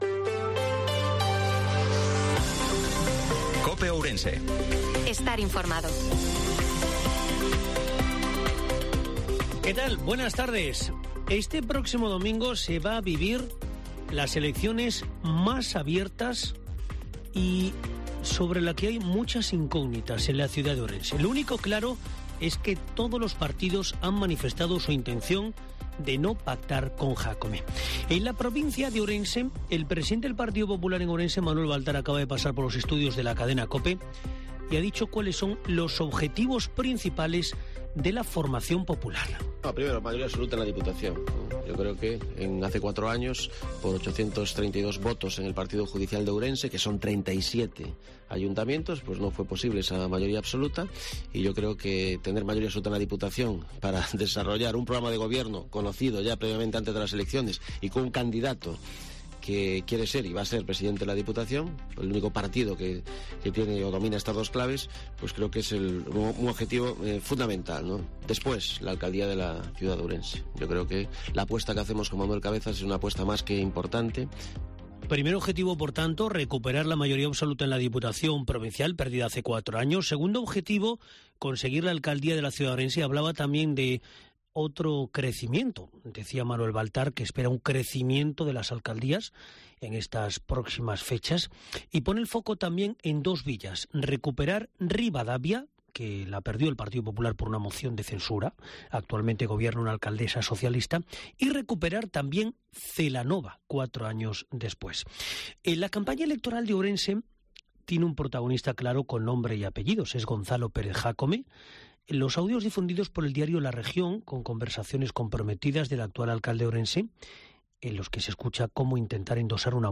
INFORMATIVO MEDIODIA COPE OURENSE-26/05/2023